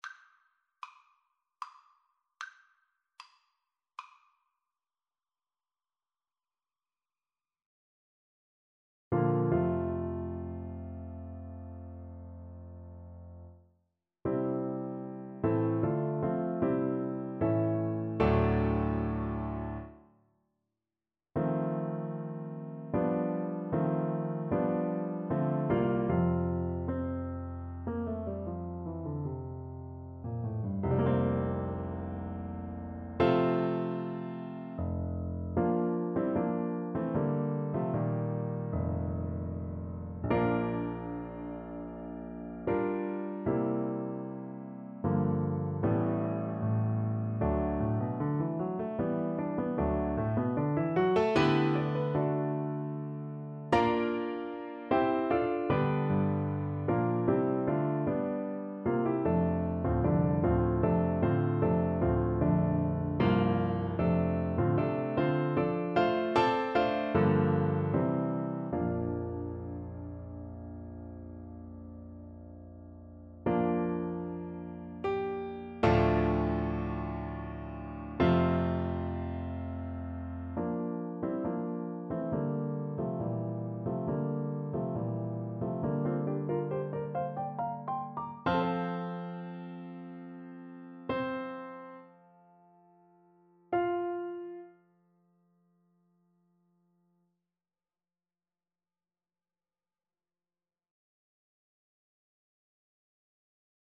• Unlimited playalong tracks
Trs calme et doucement expressif =76
Classical (View more Classical Clarinet Music)